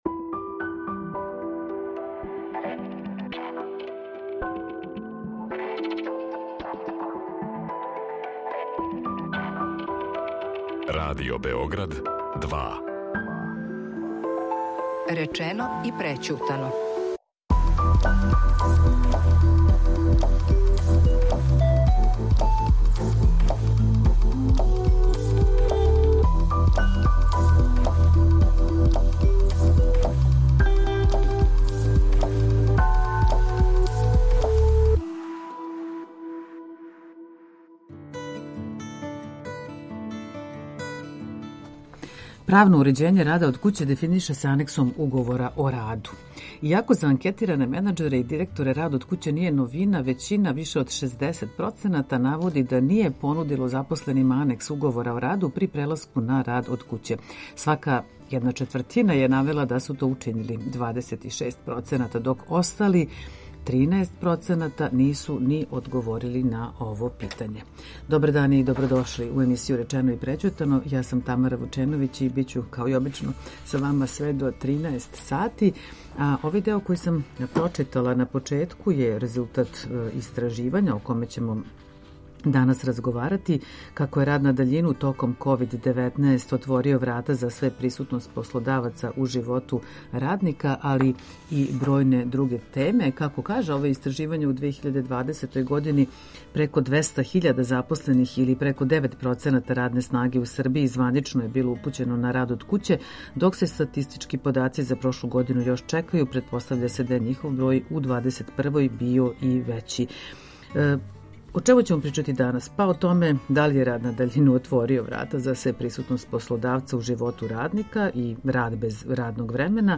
Истраживање које је о овој теми реализовао Центар за истраживање јавних политика - биће полазна основа за наш данашњи разговор и тражење одговора на питања.